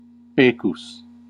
Ääntäminen
IPA : /ˈkæt(ə)l/